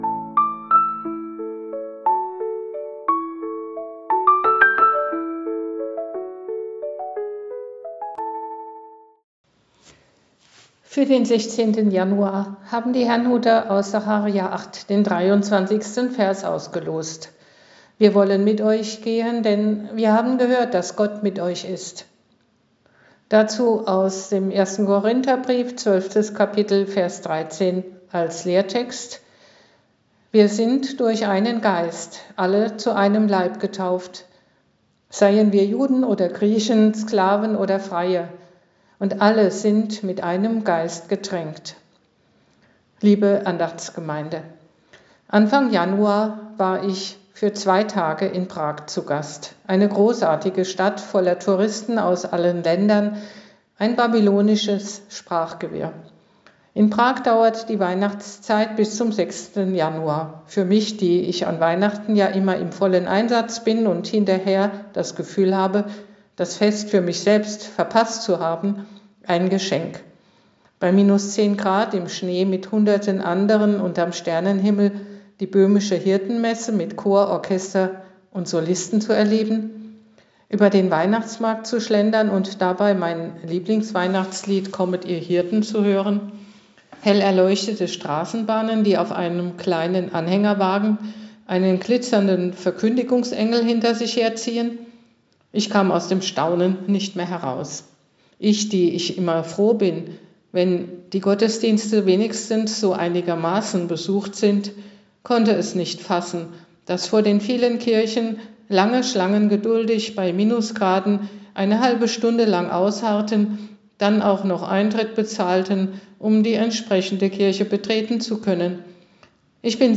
Losungsandacht für Freitag, 16.01.2026